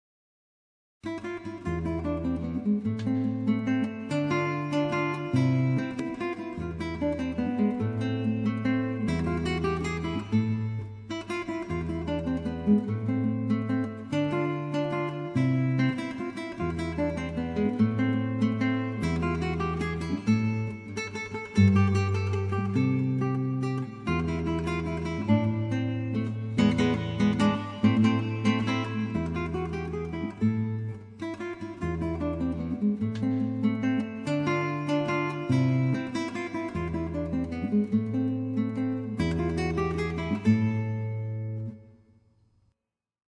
• 1 en 2 gitaren